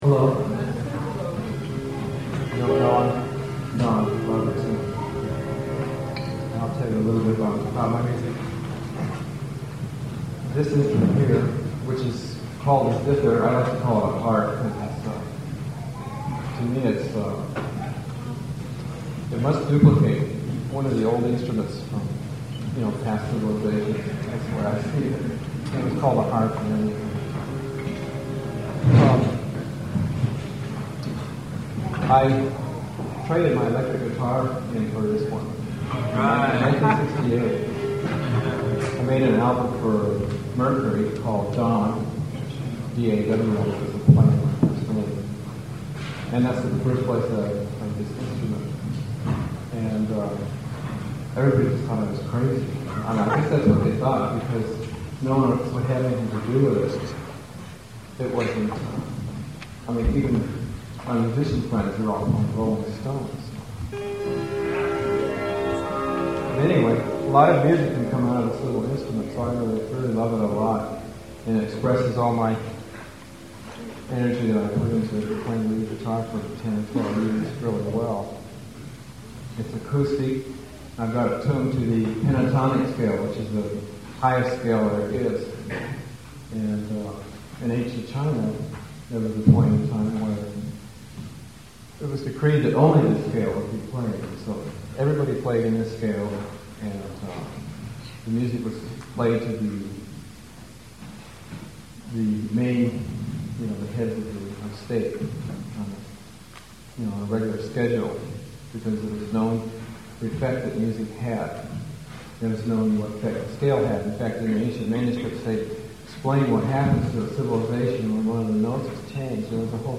Listen to the December 7th, 1980 Concert